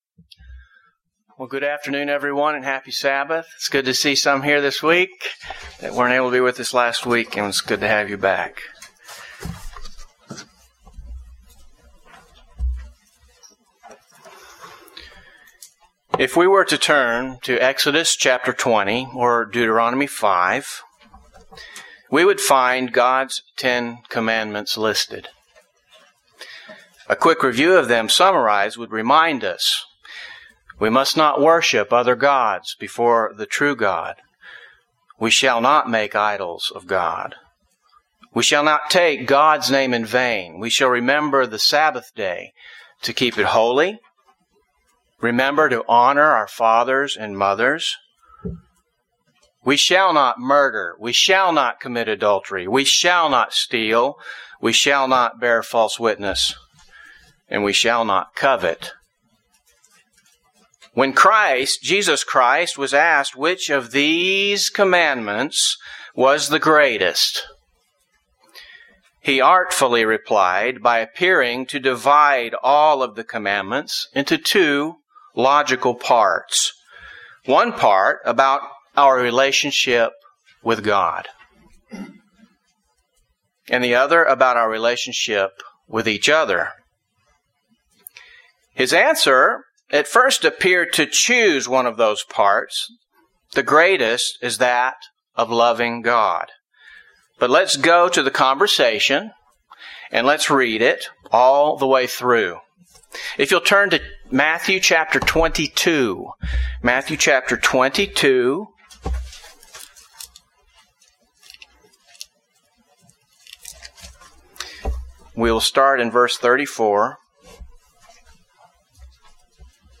UCG Sermon Godly Love Ten Commandments Notes PRESENTER'S NOTES If we were to turn to Exodus Chapter 20, or Deuteronomy Chapter 5, we would find God’s Ten Commandments listed.